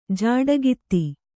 ♪ jāḍagitti